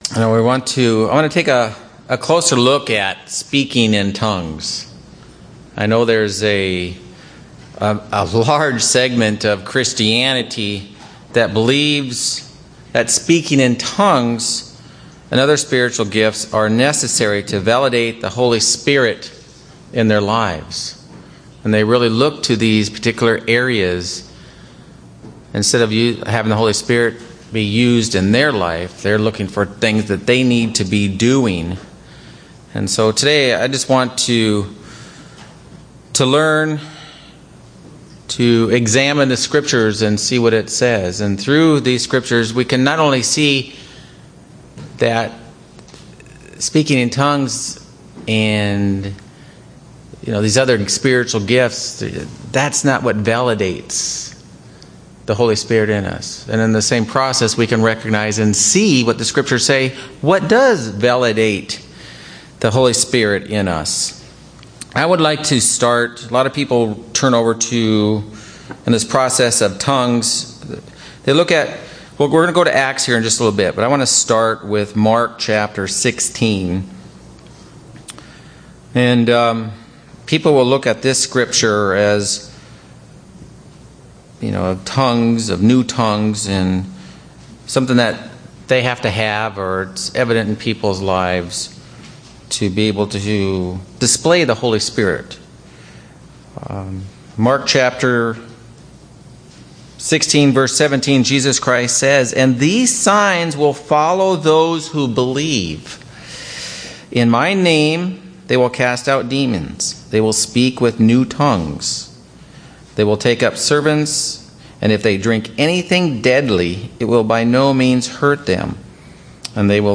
A Bible study on the topic of speaking in tongues
Print A Bible study on the topic of speaking in tongues UCG Sermon Studying the bible?